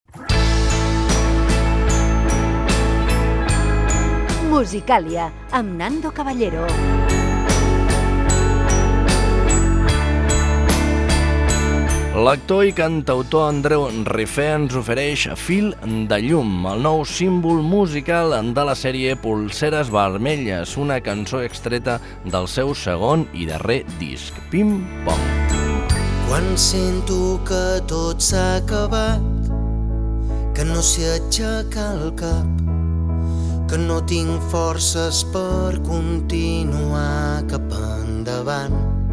657e0c2b19cc89dc184443abc808b5d9df3b4479.mp3 Títol La Xarxa Emissora La Xarxa Cadena La Xarxa Titularitat Pública nacional Nom programa Musicàlia Descripció Indicatiu del programa i presentació d'un tema musical.